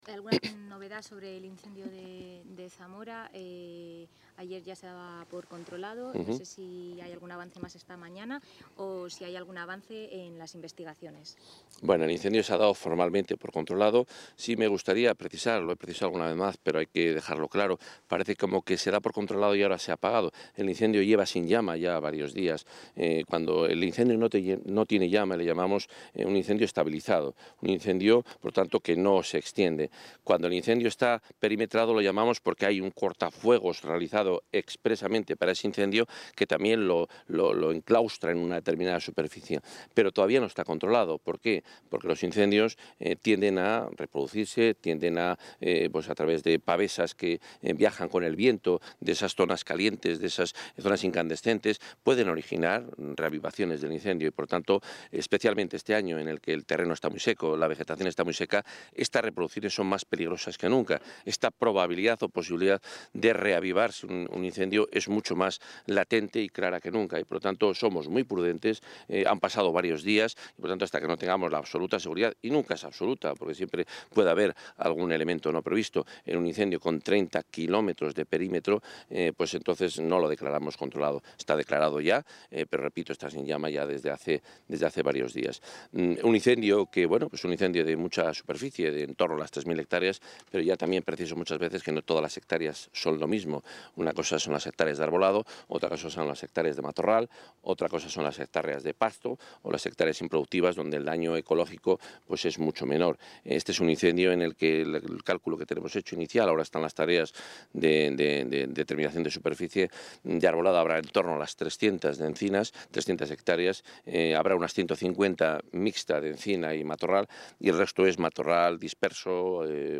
Declaraciones consejero de Fomento y Medio Ambiente.